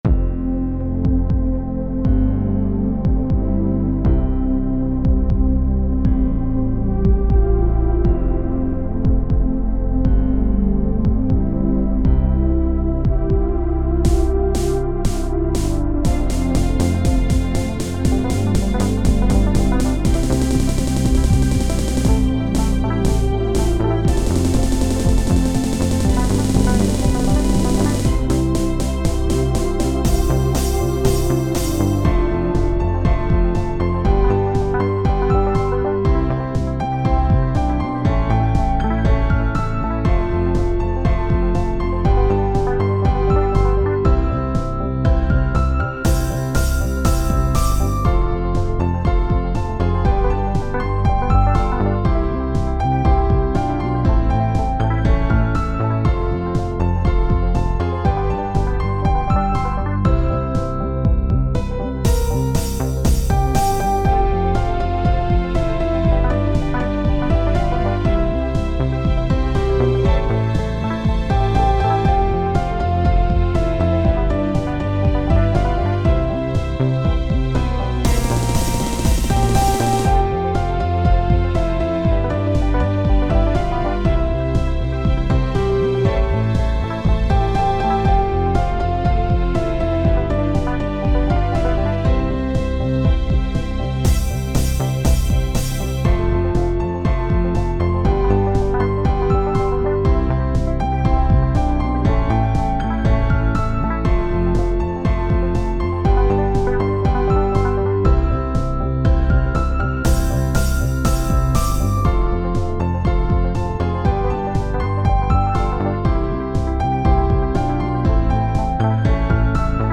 / Classical / Orchestral